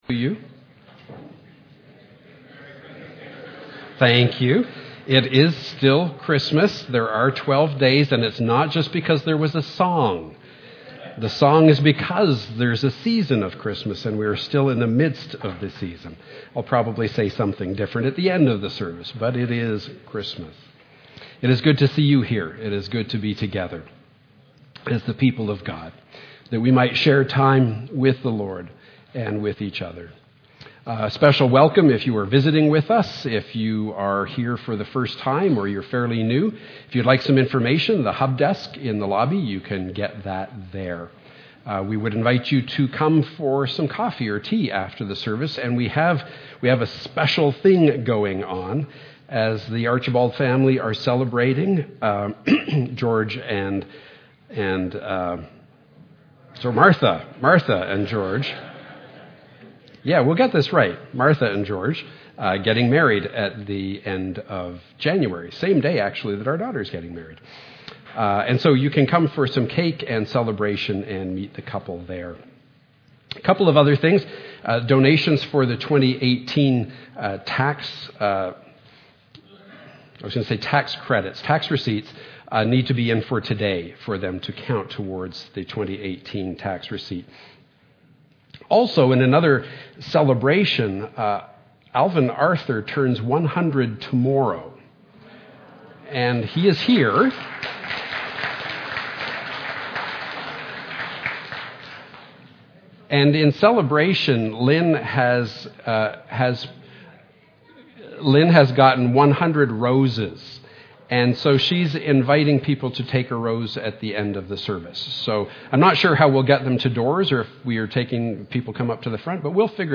St. Paul's Choir and Manotick Brass Christmas Special Service